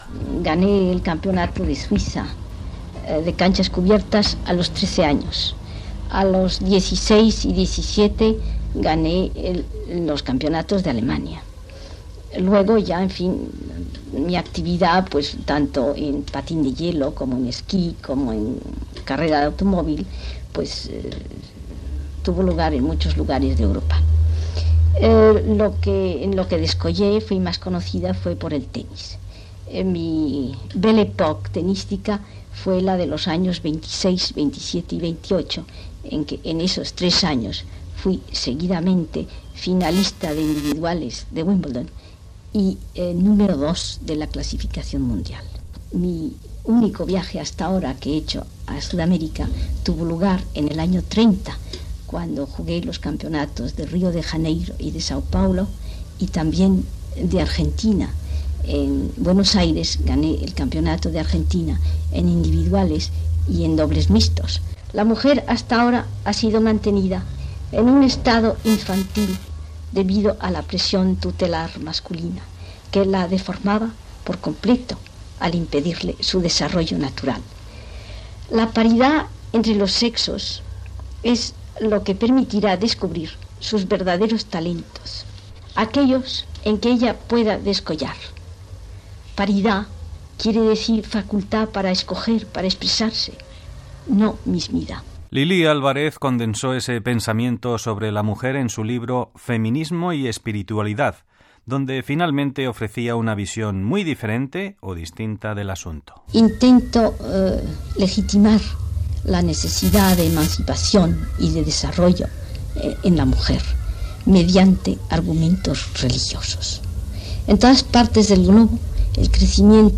Entreteniment
Fragment extret del programa "Audios para recordar" de Radio 5 emès el 17 de març del 2014.